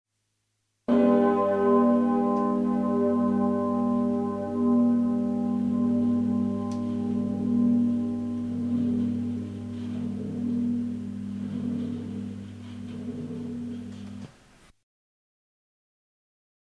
Einige weitere Hörbeispiele zu verschiedenen Glocken:
Sancta-Maria-Glocke Konstanz [266 KB]